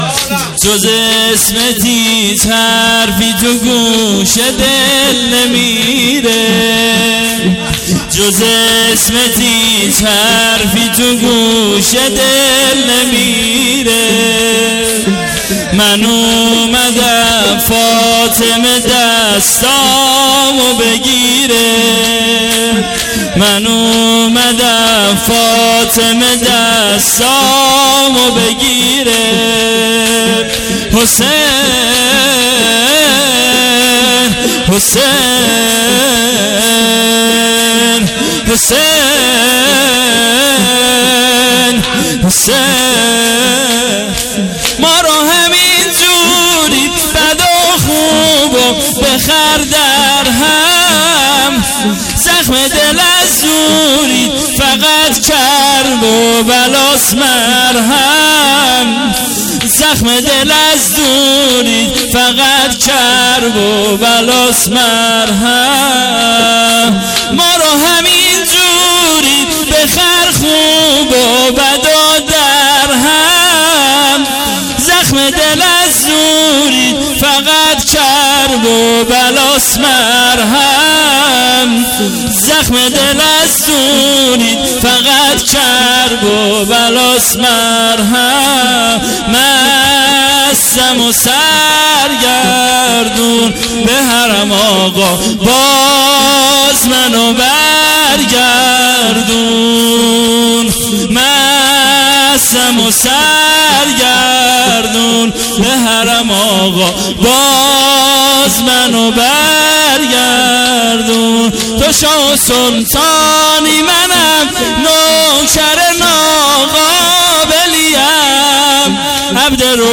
ایام فاطمیه 99